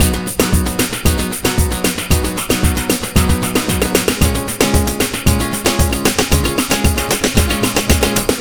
Ala Brzl 3 Full 3a-G#.wav